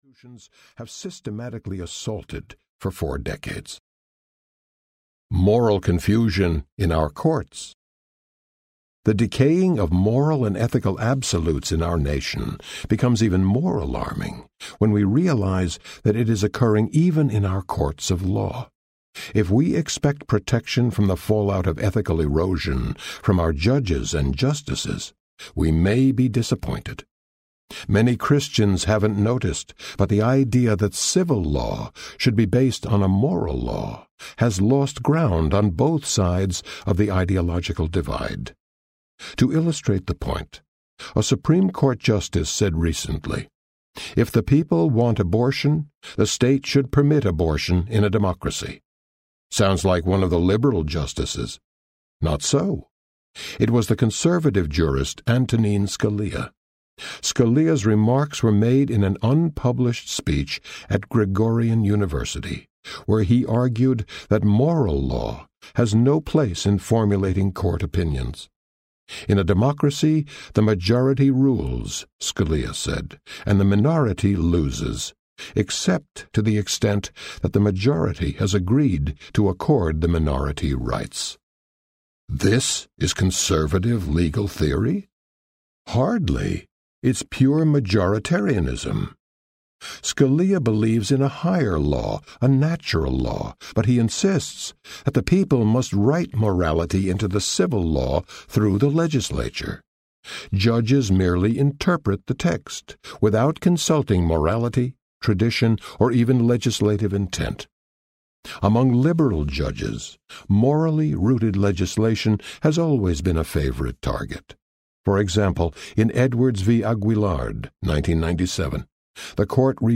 The Sky Is Not Falling Audiobook